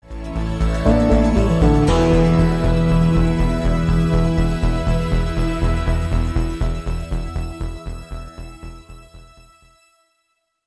Веселые Мощные Энергичные
Рейв Хардбасс